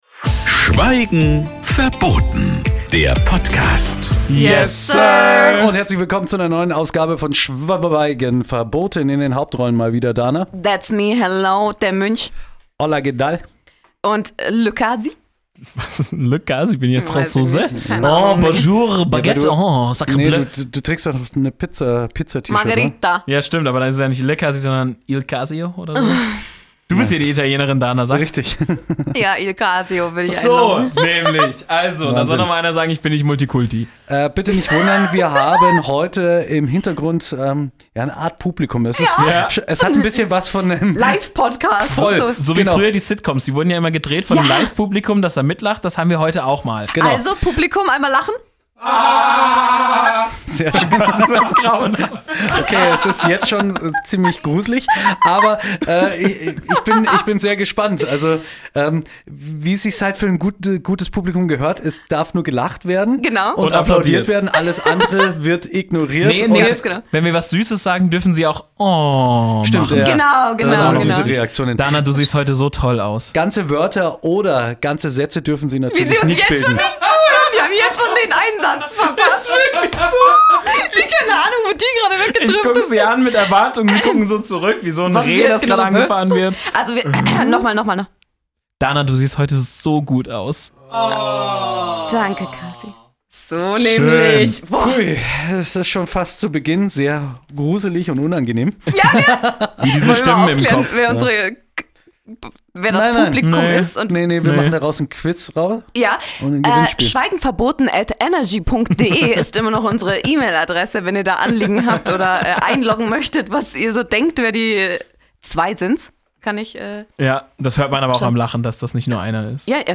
Diese Folge Schweigen Verboten wurde gedreht vor einem Live Publikum.